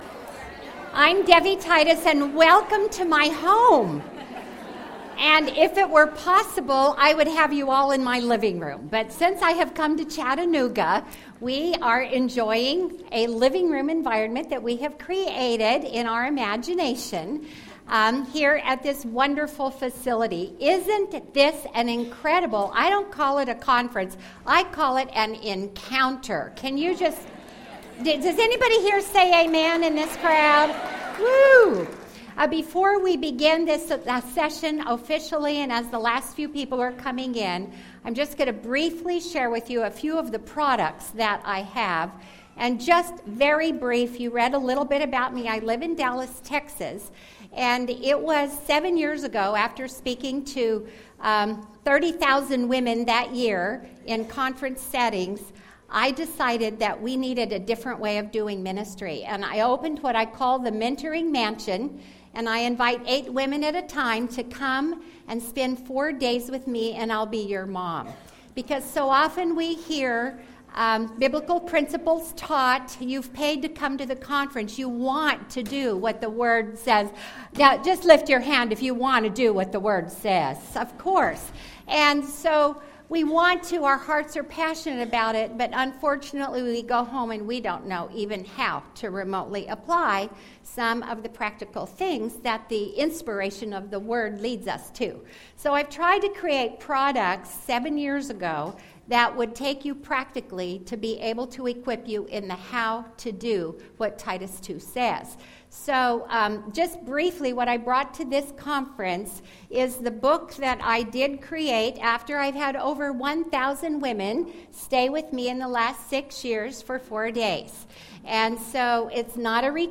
The Table Experience: Creating Deeper Relationships Through Hospitality | True Woman '10 Fort Worth | Events | Revive Our Hearts